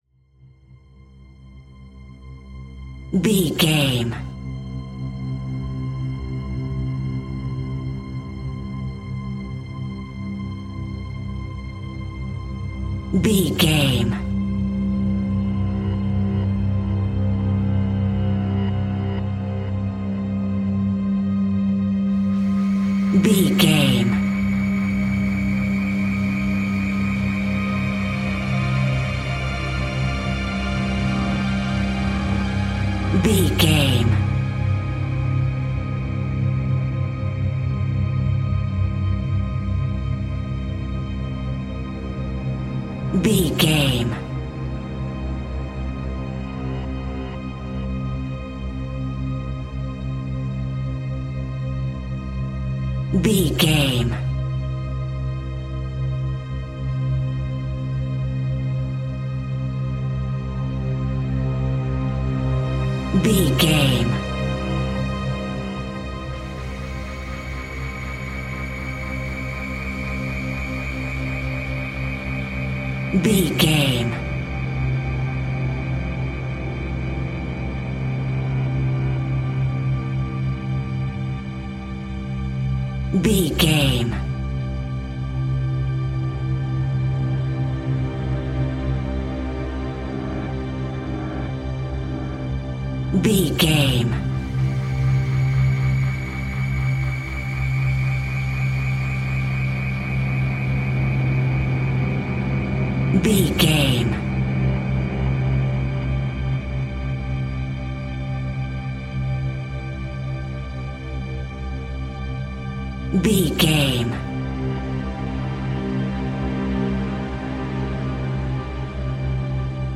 Diminished
scary
tension
ominous
dark
suspense
haunting
eerie
strings
synthesiser
violin
cello
double bass
ambience
pads